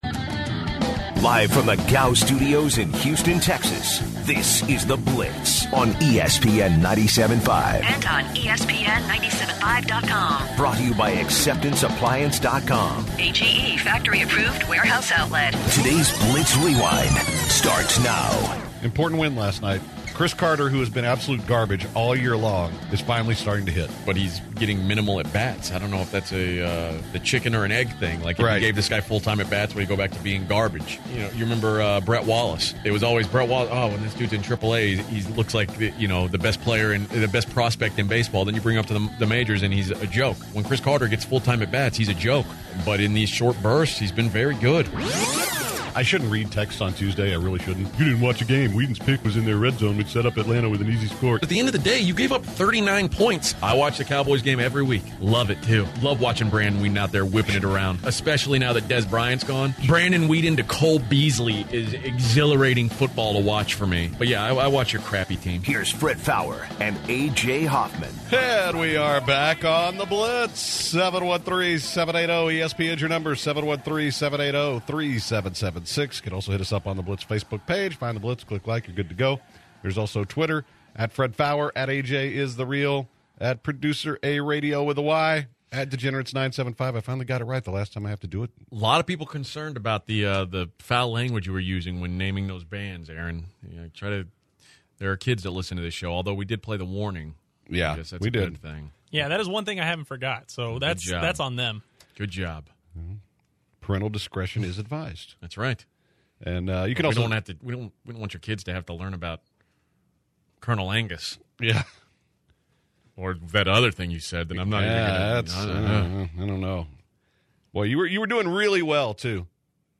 Hour 3 starts off with The Blitz Rewind and some phone calls influencing Michigan and Big 10 football discussions.